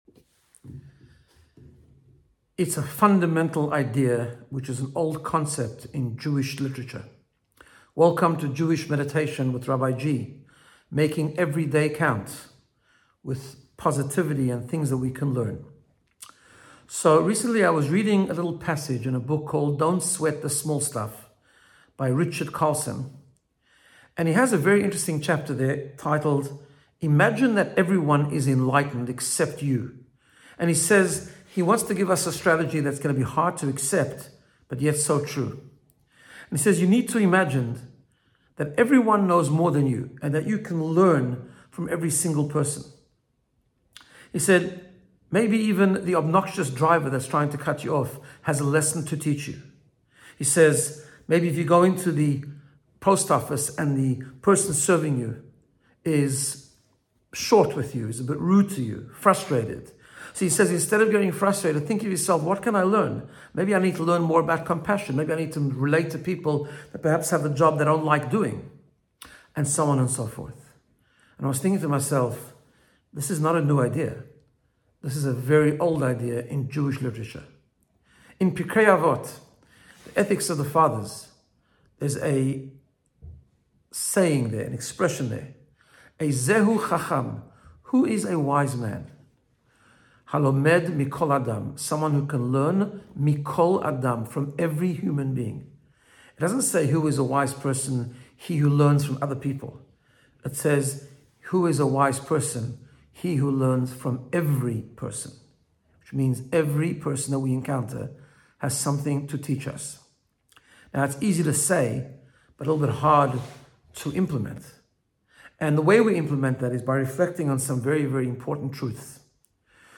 Jewish Meditation